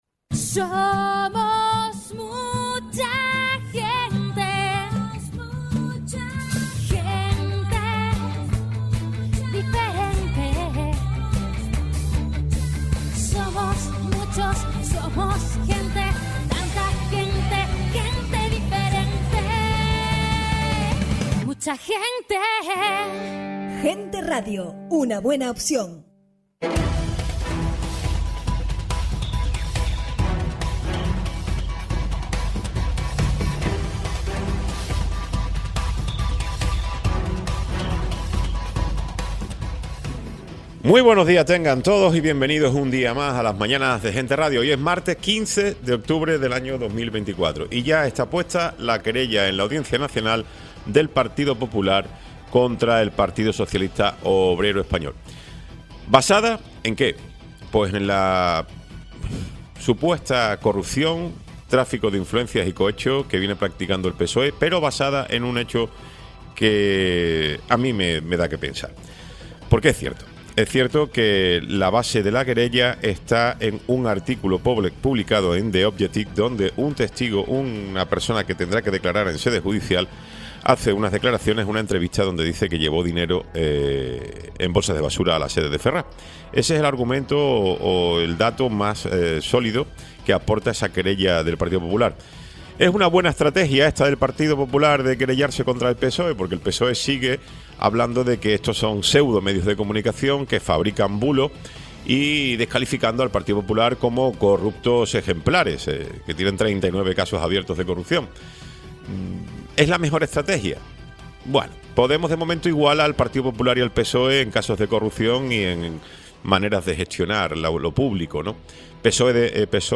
Programa sin cortes